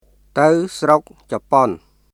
[タウ・スロック・チャポン　tə̀w srok capon]